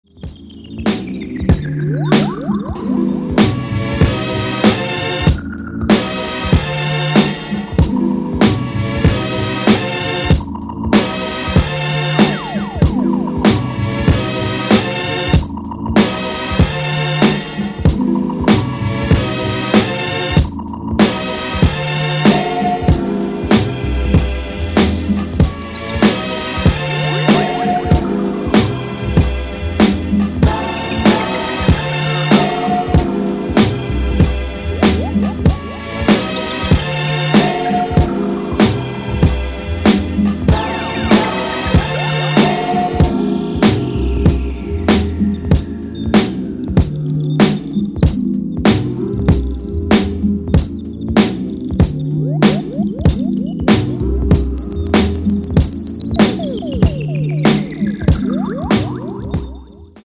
hip hop soundscapes